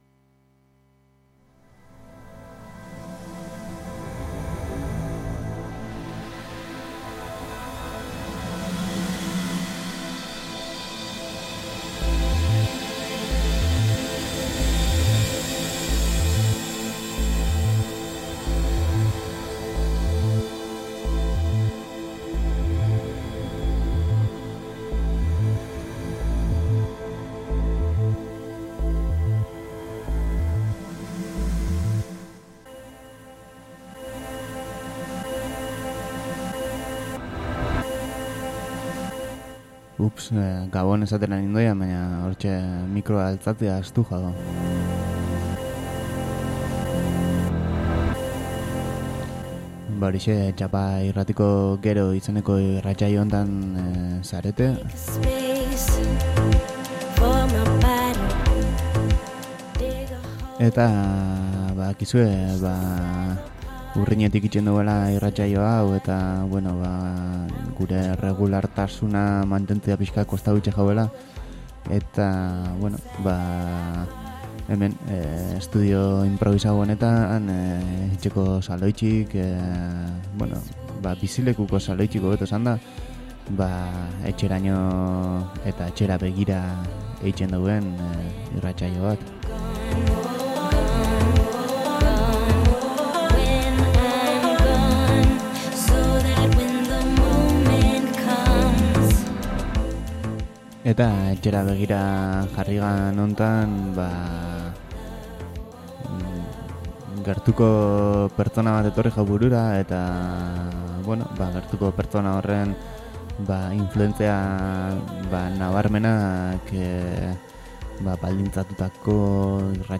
Elektronikaren barnean jarraitzen dugu, azkenaldian bezala, eta honen barruan aurkitzen dugun inframundu batean murgildu gara: erremixak, nahasketak, moldaketak. Beste norbaitek egindako musika hartu eta zenbait osagai kendu, beste zenbait gehitu, batidoran sartu eta kantu berriak sortu.